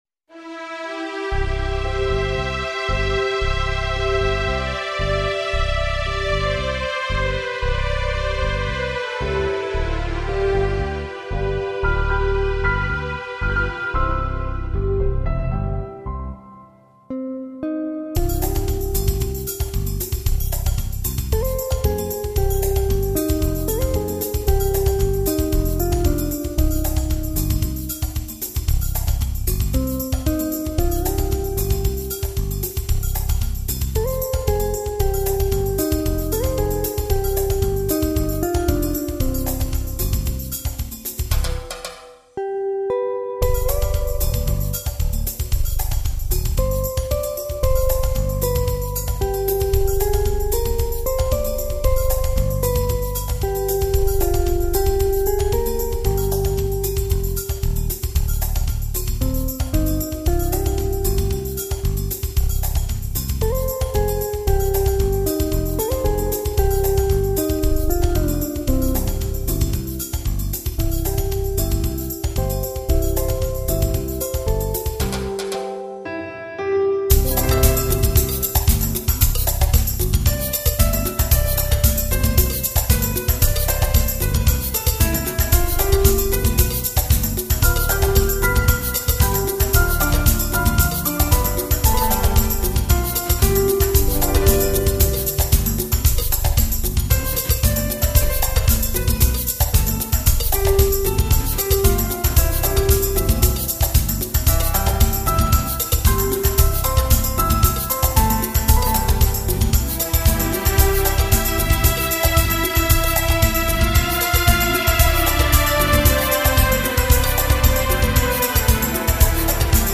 欧美轻音乐专辑